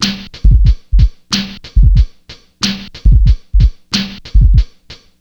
BEAT 3 92 02.wav